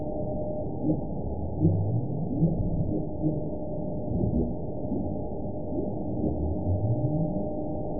event 918038 date 04/27/23 time 14:46:49 GMT (2 years ago) score 9.45 location TSS-AB03 detected by nrw target species NRW annotations +NRW Spectrogram: Frequency (kHz) vs. Time (s) audio not available .wav